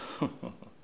hahaha-mesquin_01.wav